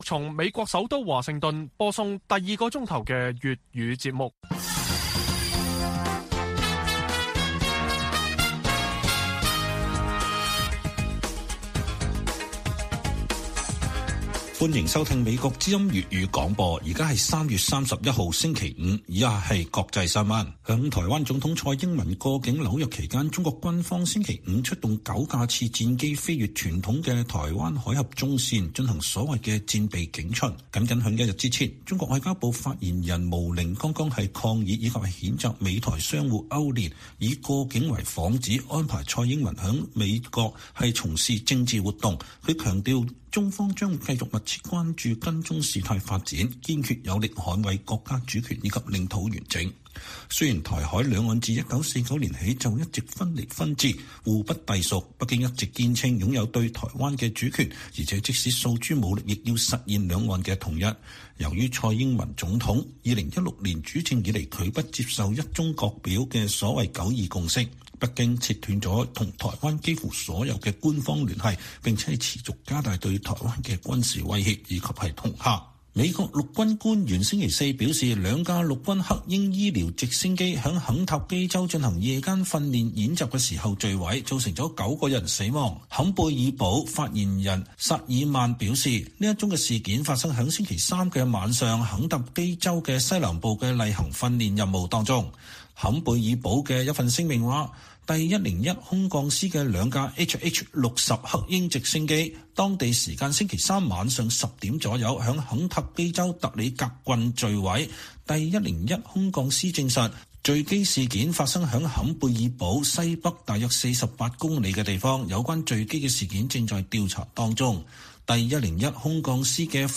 粵語新聞 晚上10-11點: 解放軍戰機在蔡英文過境紐約期間飛越台海中線